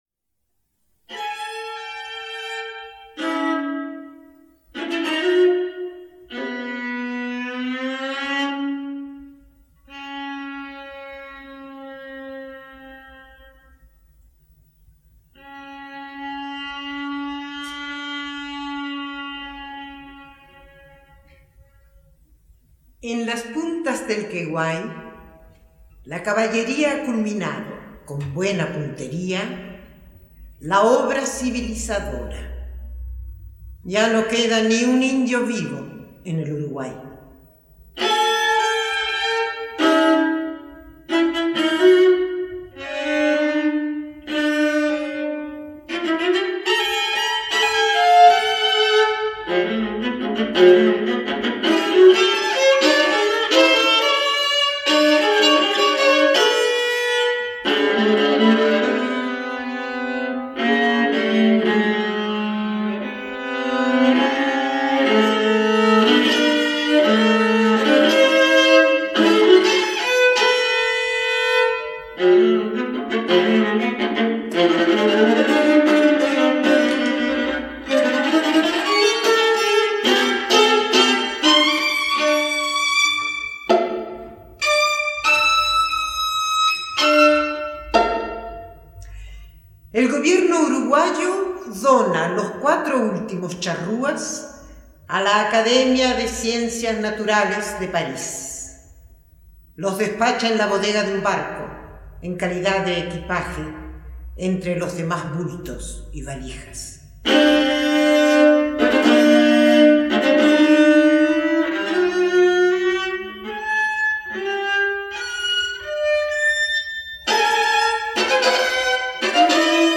Tacuabé para viola y recitante